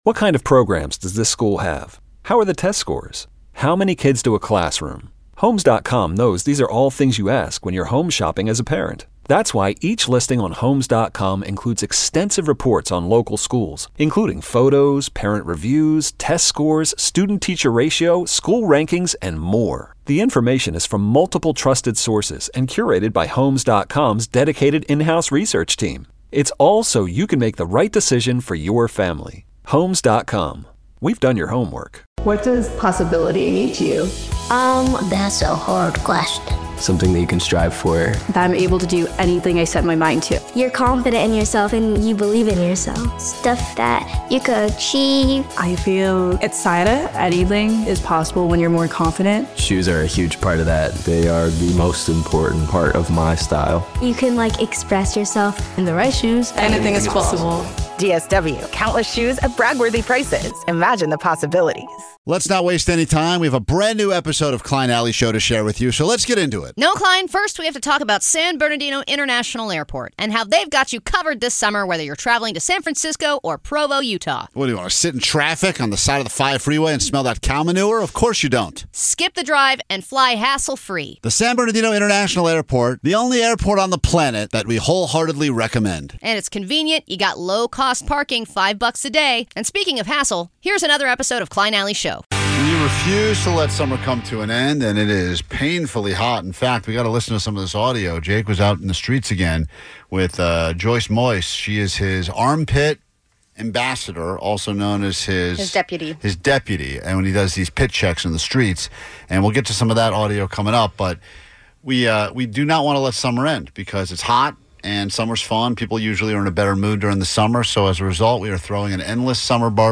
the show is known for its raw, offbeat style, offering a mix of sarcastic banter, candid interviews, and an unfiltered take on everything from culture to the chaos of everyday life.